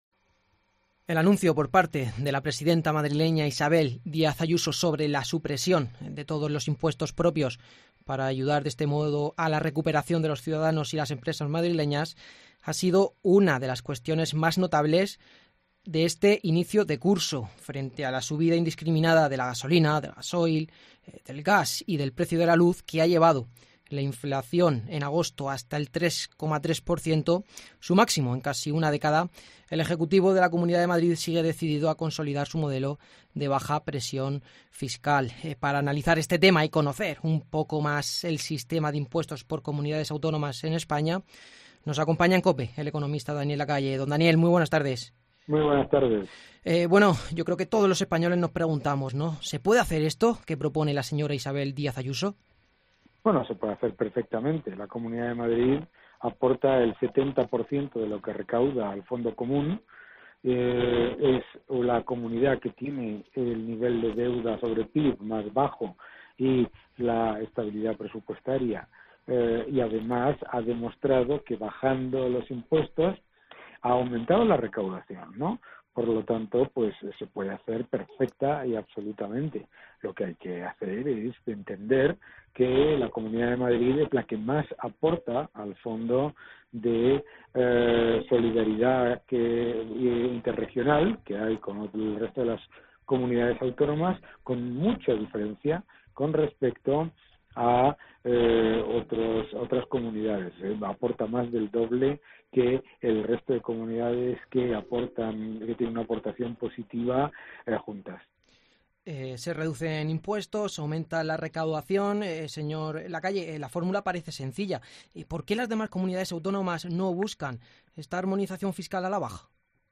El economista Daniel Lacalle, en declaraciones a COPE, ha asegurado que la Comunidad de Madrid ha demostrado que “bajando los impuestos es posible recaudar más”, por eso es una propuesta que “se puede llevar a cabo perfecta y absolutamente”.